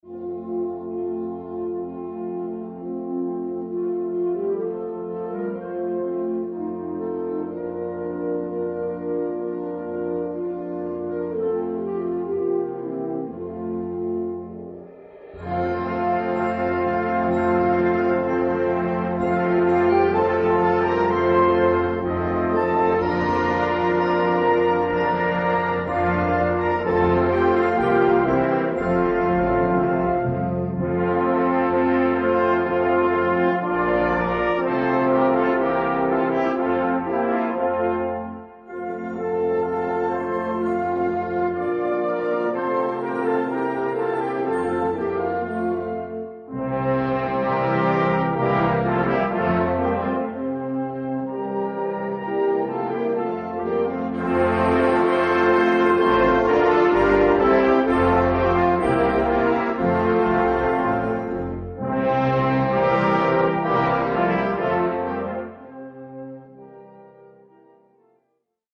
Gattung: Weihnachtliche Blasmusik
Besetzung: Blasorchester